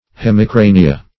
Hemicrania \Hem`i*cra"ni*a\, n. [L.: cf. F. h['e]micr[^a]nie.